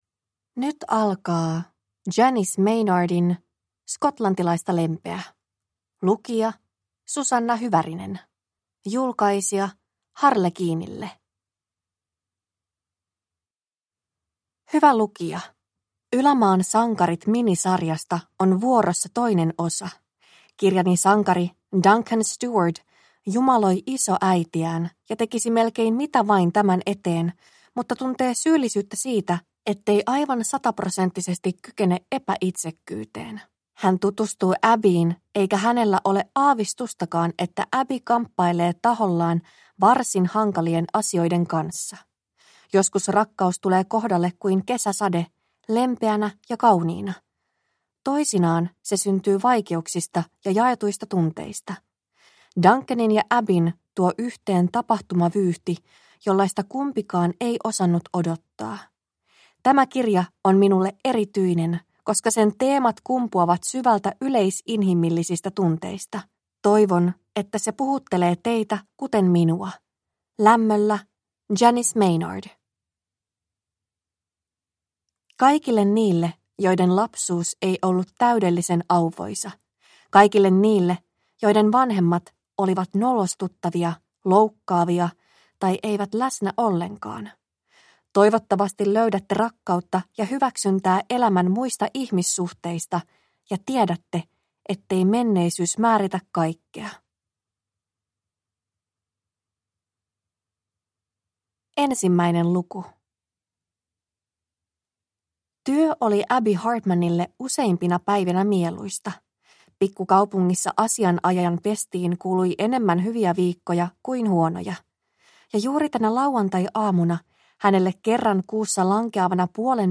Skotlantilaista lempeä – Ljudbok – Laddas ner